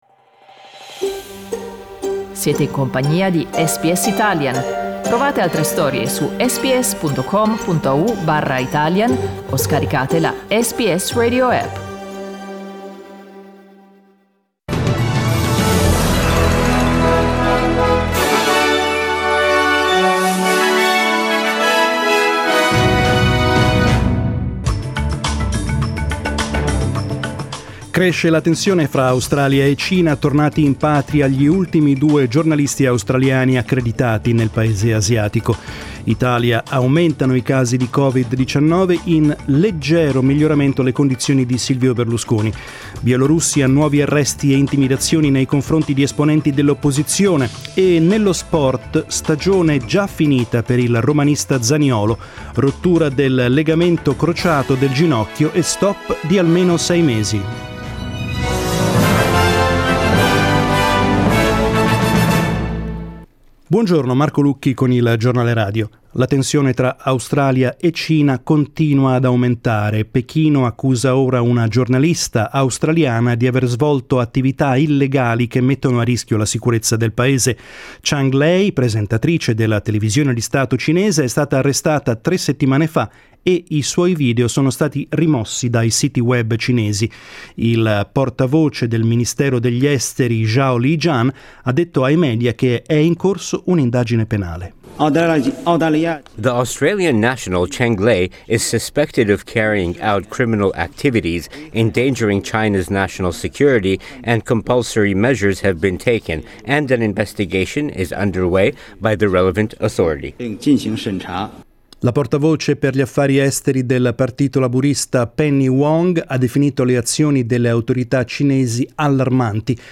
The Italian news bulletin that went to air this morning on SBS Radio.